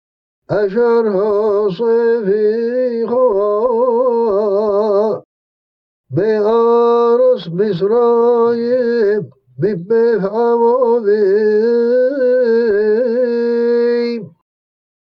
כך היא הגיית ה־צ במסורות ההגייה של קריאת המקרא בפי עֲדות ישראל שבתחום השפעת הערבית, כגון מסורת ההגייה הספרדית לתפוצותיה ומסורת ההגייה התימנית.